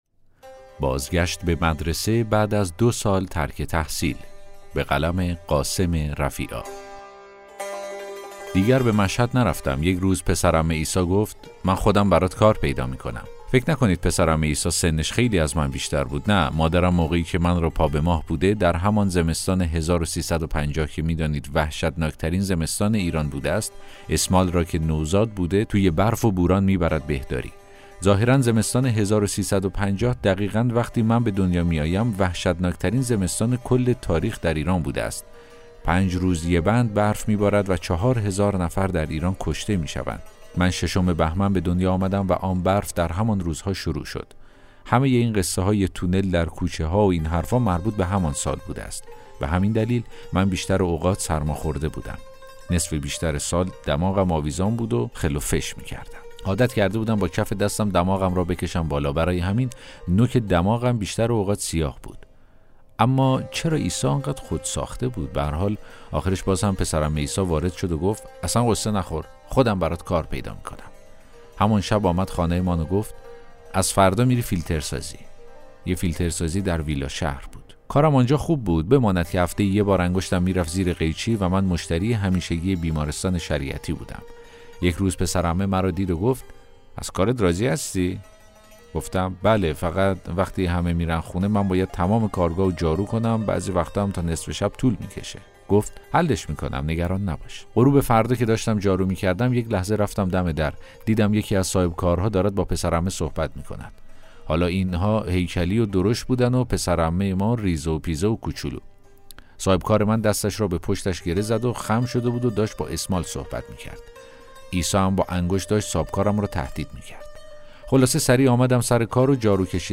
داستان صوتی: بازگشت به مدرسه، بعد از ۲ سال ترک تحصیل